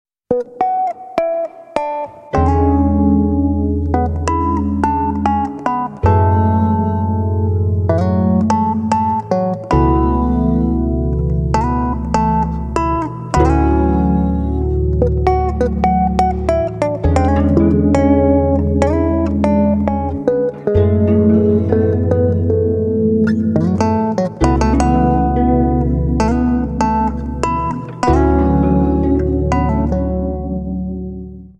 Electric Bass